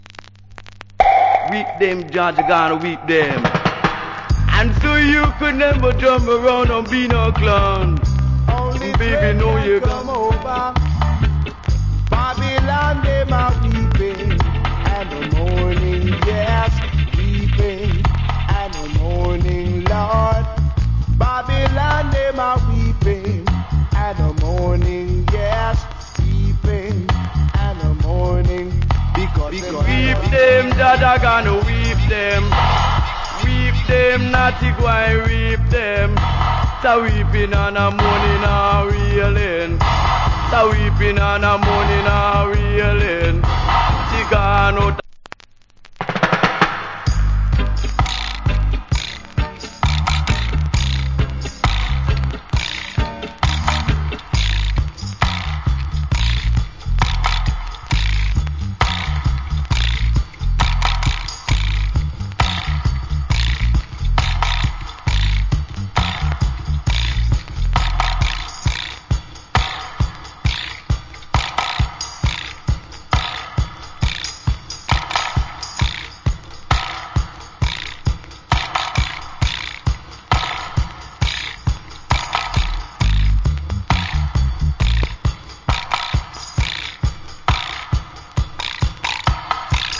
Nice DJ.